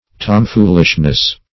Tomfoolishness \Tom`fool"ish*ness\, n. Same as tomfoolery .